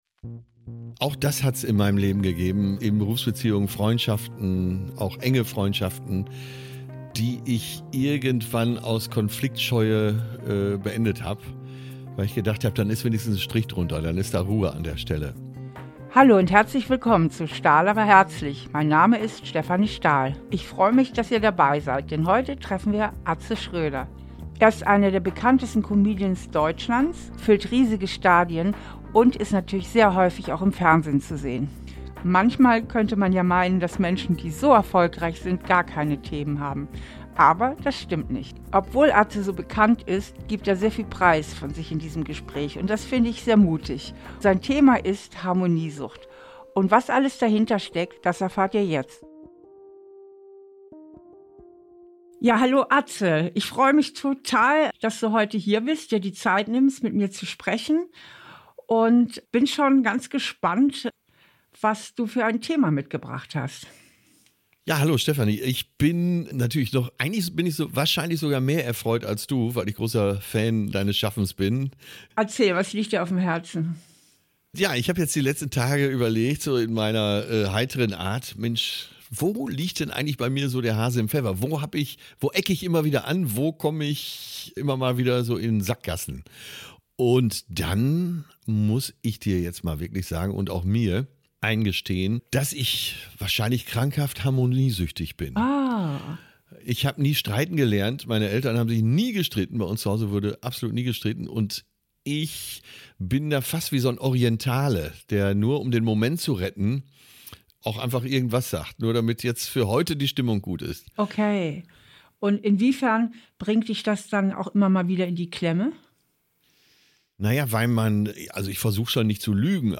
Comedian Atze Schröder legt sich bei Stefanie Stahl auf die Therapiecouch.
Im Gespräch mit Steffi Stahl findet er es heraus.